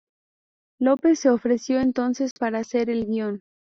Pronounced as (IPA) /ˈɡjon/